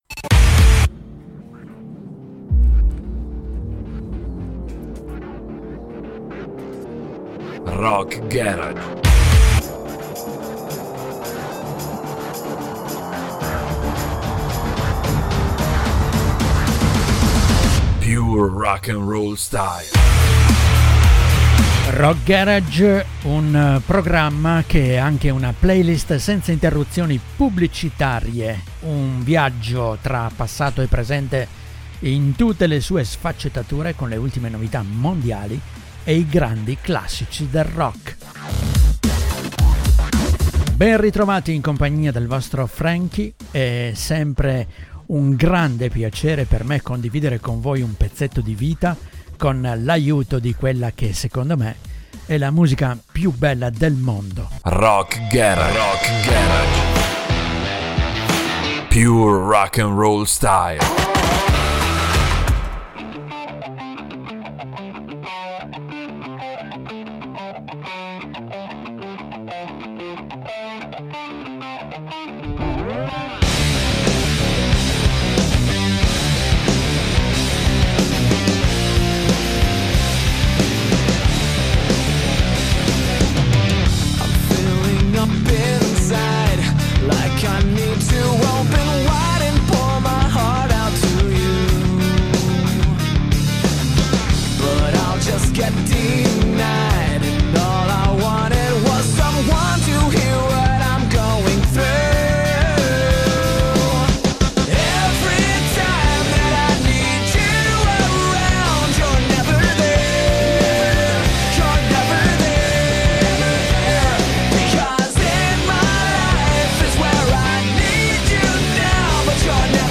una playlist senza interruzioni
grandi classici del rock